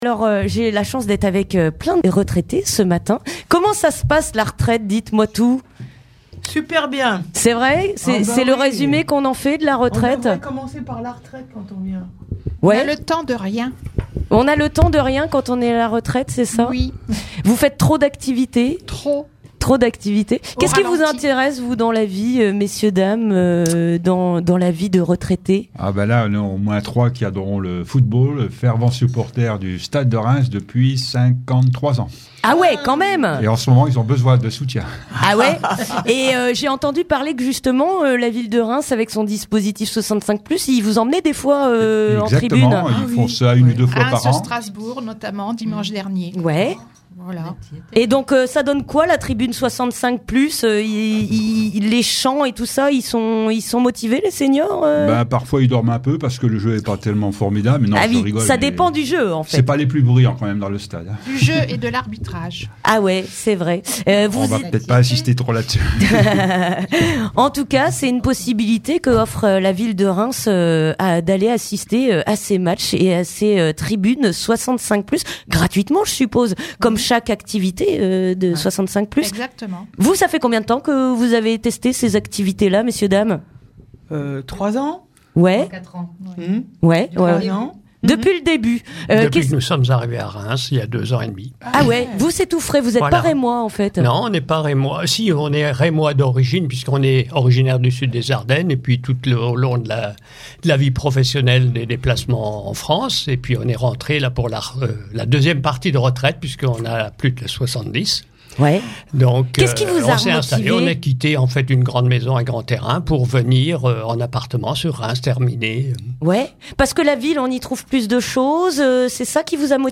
échanges en direct (6:18)
Radio Primitive accueillait des séniors membres du dispositif 65+ de la ville de Reims pour une découverte de la radio.
Après une petite visite, ils et elles ont pu assister au direct de mi-journée et dire quelques mots au sujet des activités proposées par la ville et des associations dans lesquelles ils et elles s'investissent.